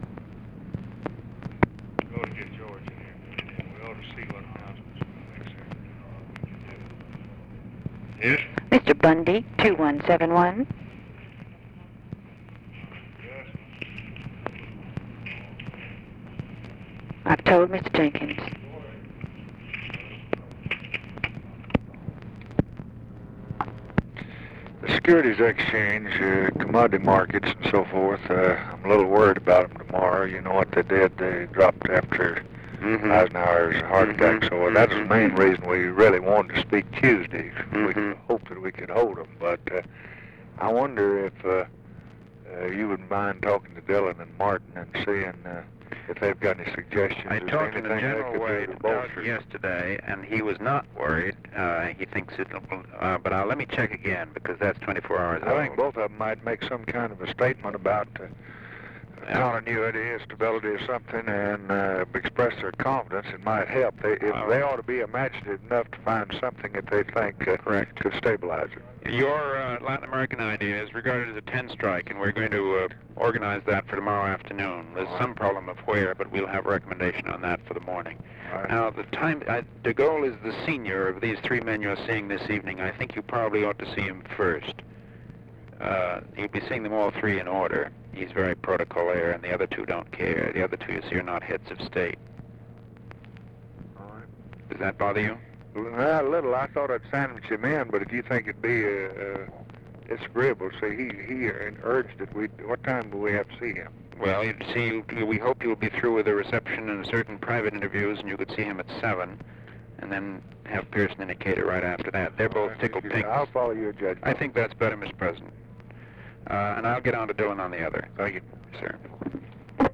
Conversation with MCGEORGE BUNDY, November 25, 1963
Secret White House Tapes